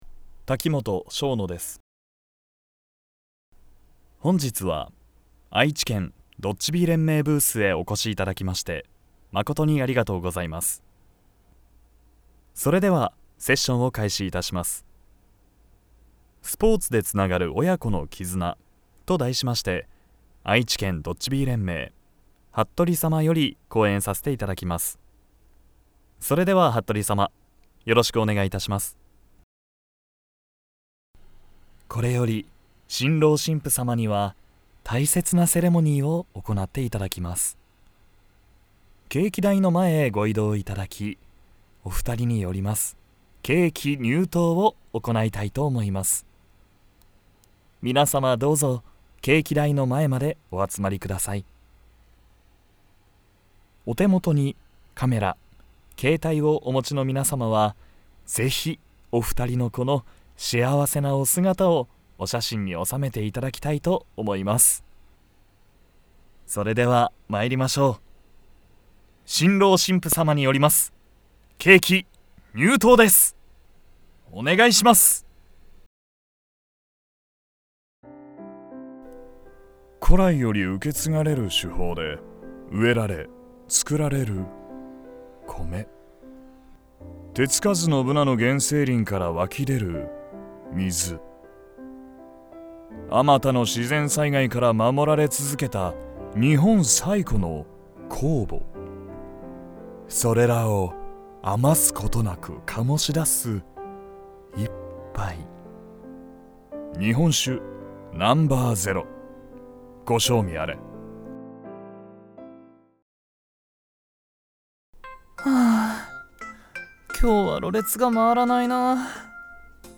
ナレーター｜MC｜リポーター